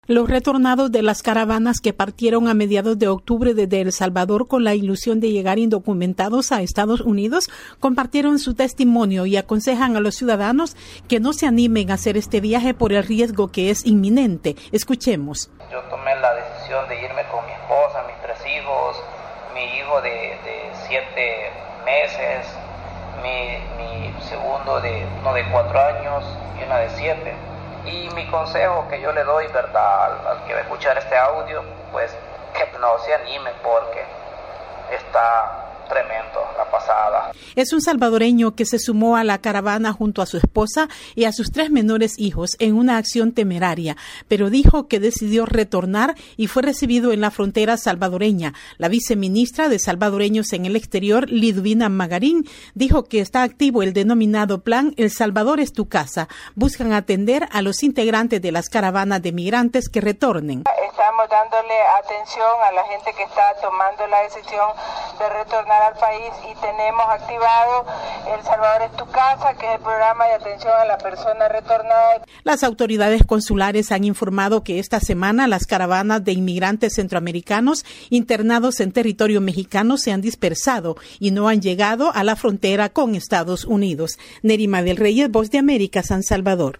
INTRO: Migrantes salvadoreños que retornan de la caravana de indocumentados que se dirige a Estados Unidos, relatan los peligros que enfrentaron. Desde San Salvador informa